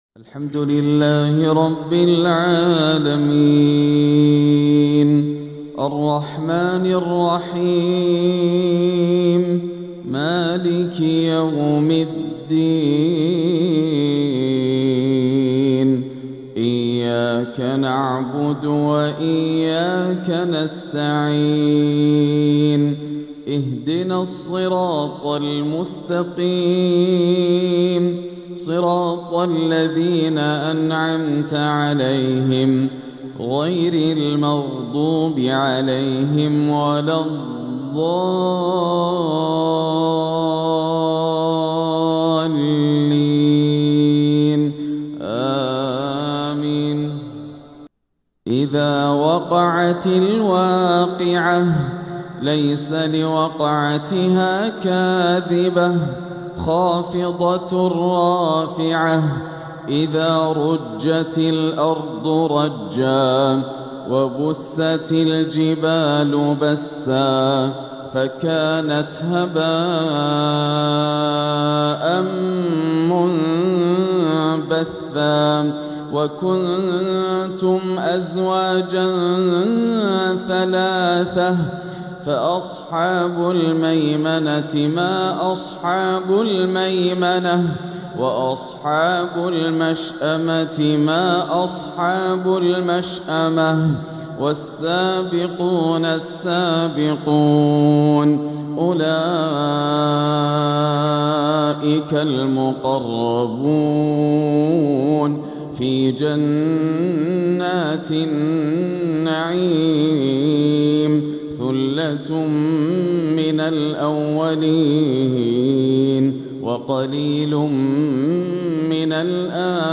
فجريات روحانية حبرها د. ياسر الدوسري بصوته العذب | من 6-12 ذو القعدة 1446هـ > إصدارات "وقرآن الفجر" > إصدارات منوعة 🕋 > المزيد - تلاوات الحرمين